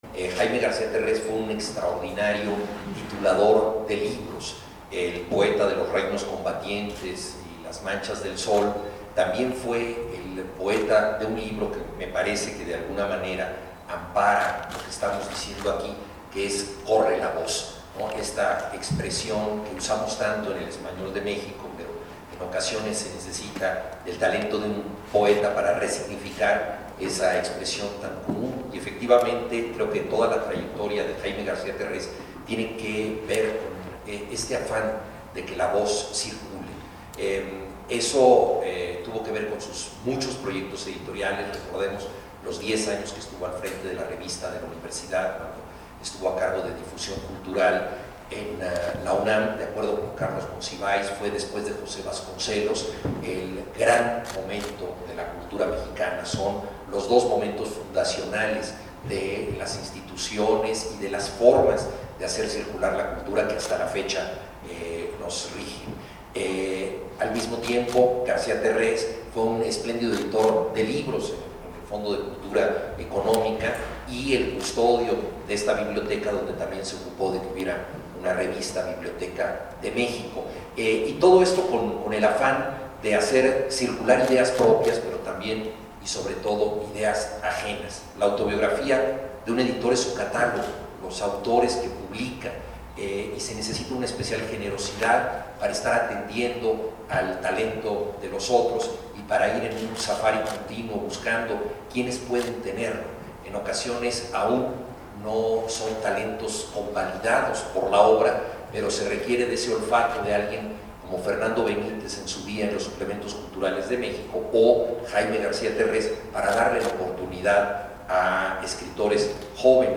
juan_villoro__escritor.mp3